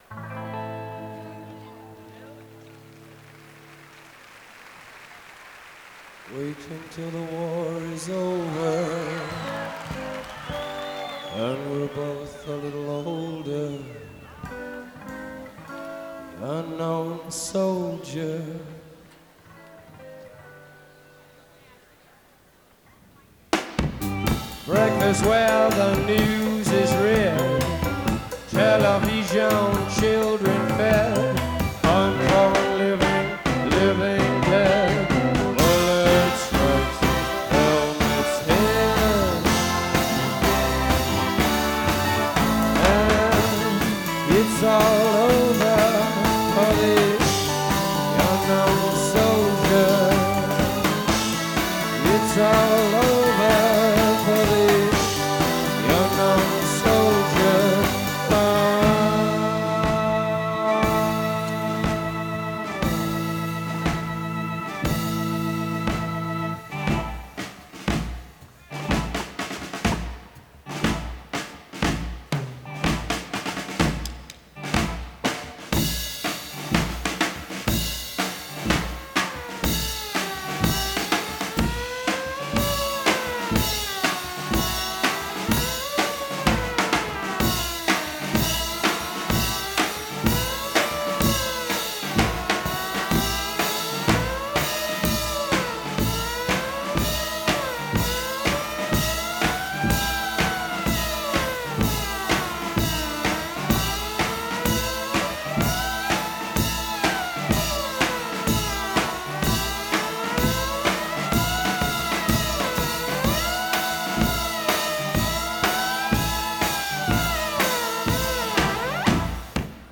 Live at the Hollywood Bowl, 1968